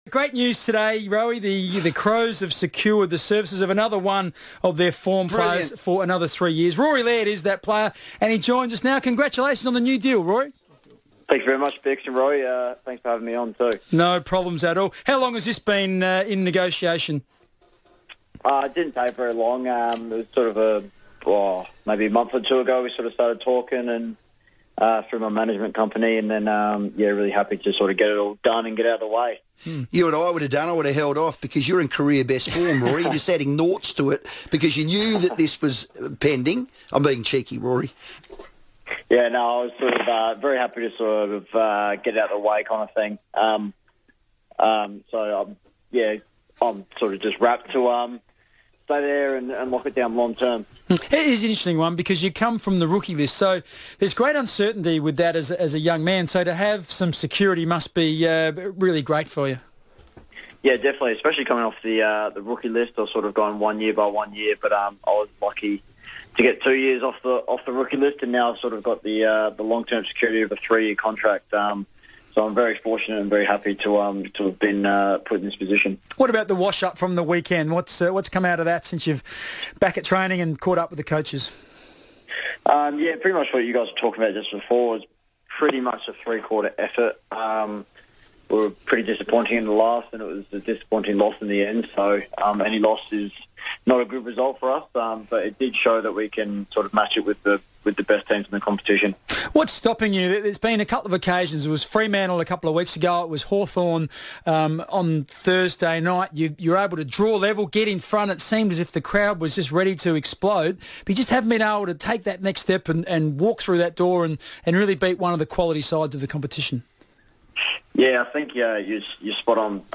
Rory Laird spoke on the FIVEaa Sports Show after re-signing with the Crows for a further three years.